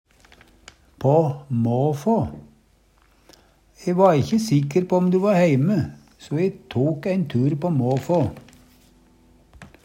på måfå - Numedalsmål (en-US)